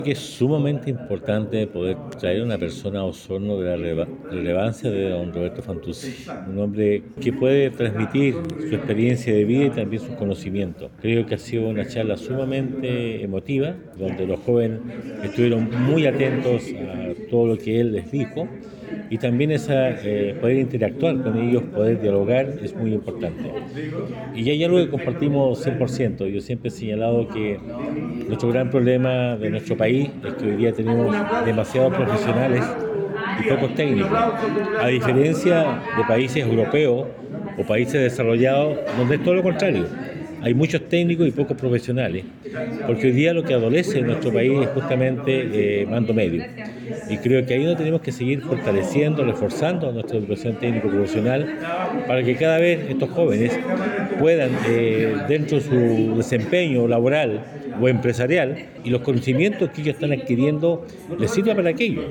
Por su parte, el Alcalde Emeterio Carrillo señaló que es un gran incentivo para los estudiantes previo al desafío que enfrentan de ingresar al mundo laboral o continuar con estudios superiores. Una experiencia que los ayuda a conocer otras visiones y contar con nuevas herramientas, tomadas de un diálogo sincero y abierto.